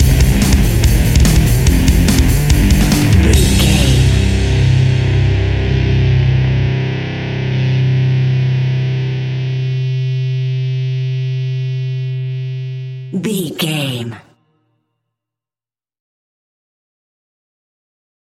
Enraged Angst Metal Rock Music Stinger.
Fast paced
Aeolian/Minor
hard rock
heavy metal
distortion
Rock Bass
heavy drums
distorted guitars
hammond organ